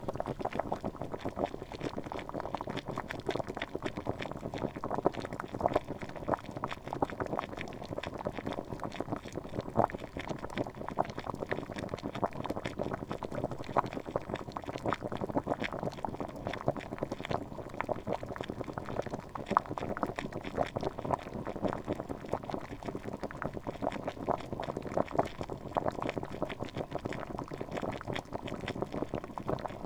SFX